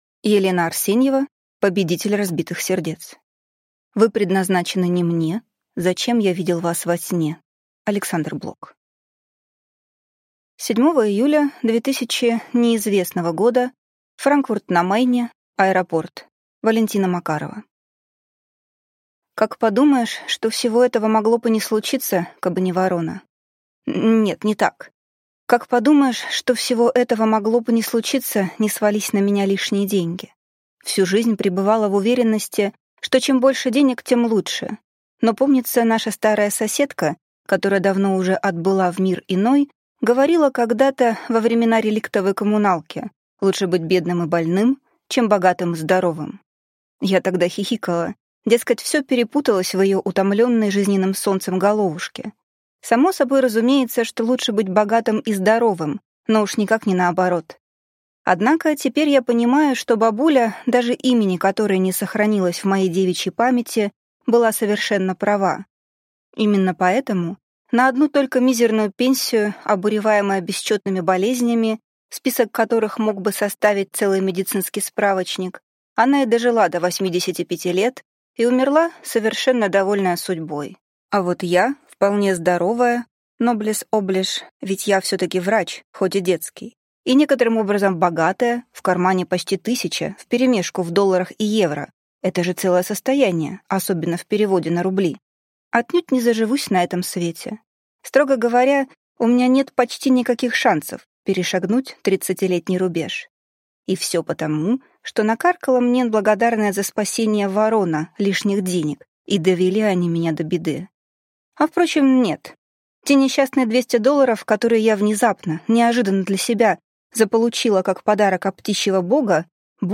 Аудиокнига Повелитель разбитых сердец | Библиотека аудиокниг